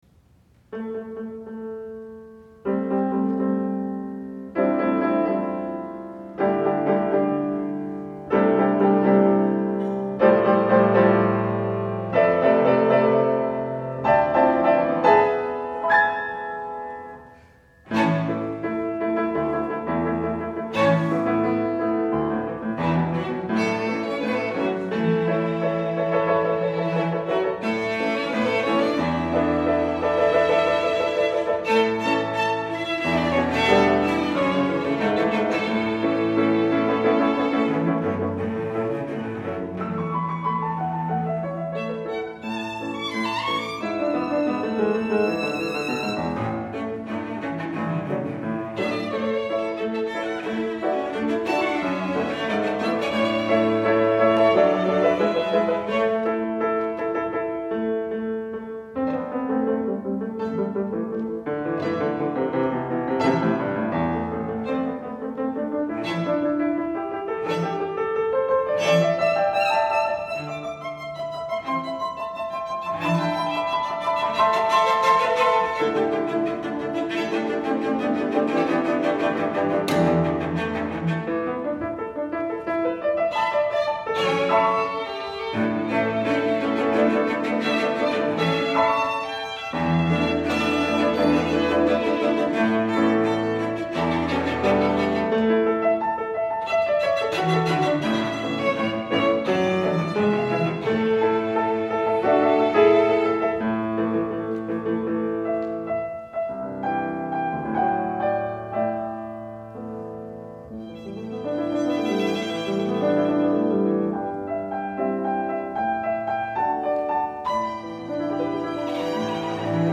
for Piano Trio (2015)
This is a joyful excursion to "The Promised Land."